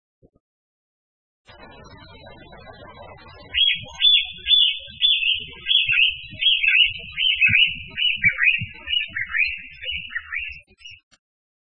〔コジュケイ〕チョットコイ，チョットコイ／ビッググイー／積雪地以外の平地から低
kojyukei.mp3